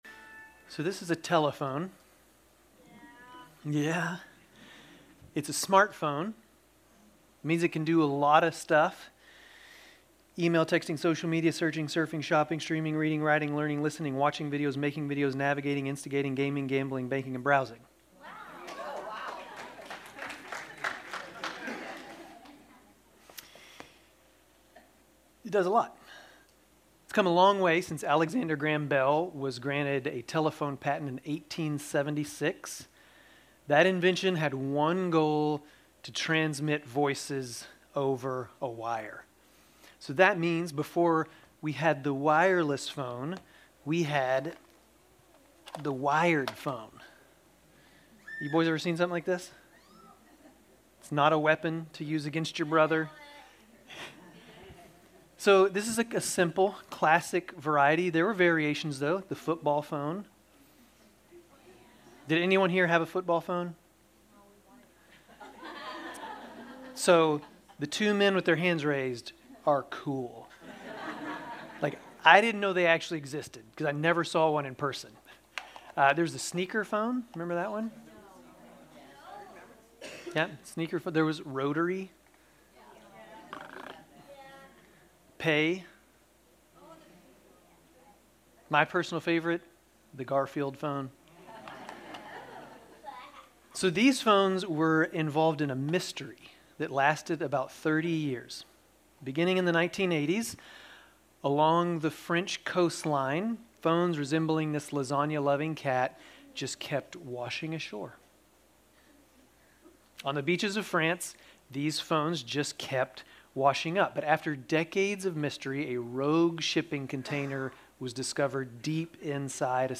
Grace Community Church Dover Campus Sermons 11_2 Dover Campus Nov 03 2025 | 00:28:54 1x 00:00 / 00:28:54 Subscribe Share RSS Feed Share Link Embed